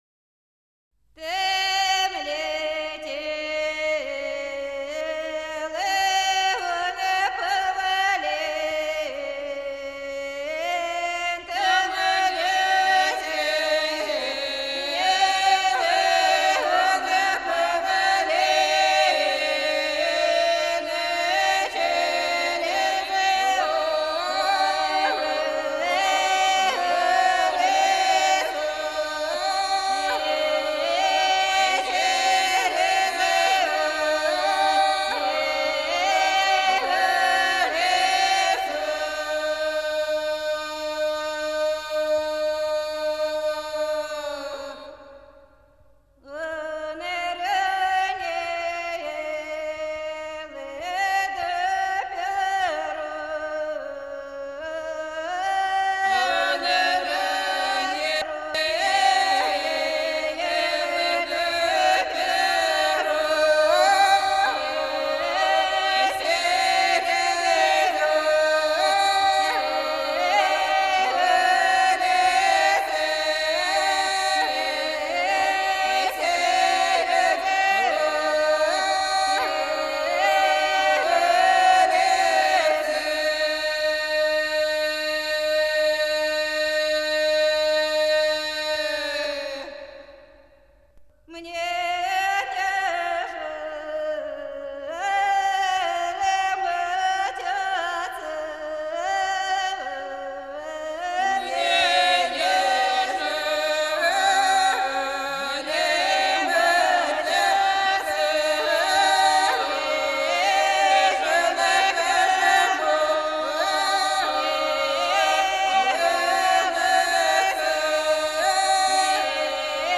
Belgorod reg. lingering song